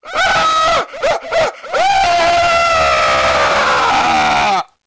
SCREAM01.WAV